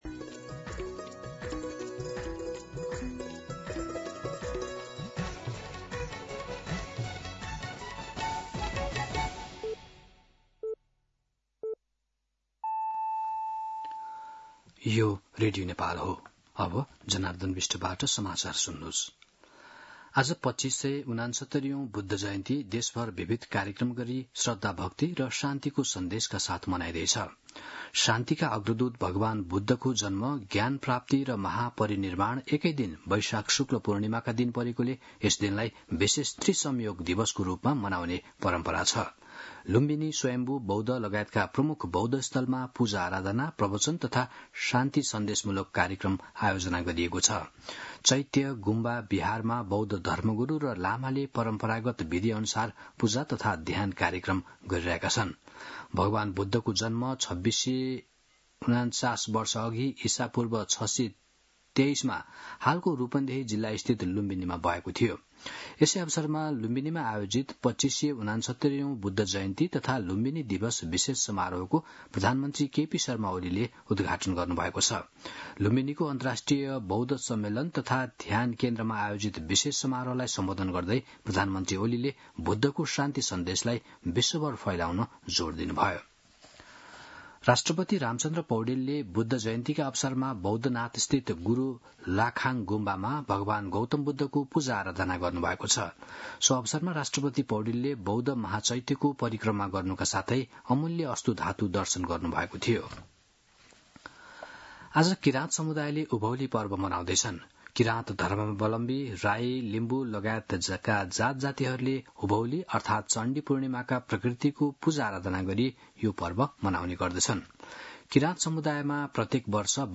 मध्यान्ह १२ बजेको नेपाली समाचार : २९ वैशाख , २०८२